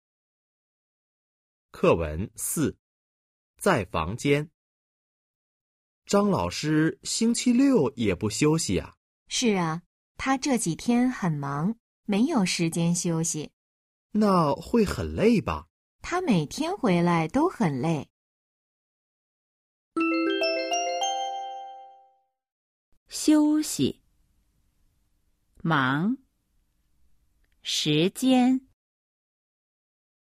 Hội thoại 4：在房间 – Trong phòng  💿 02-04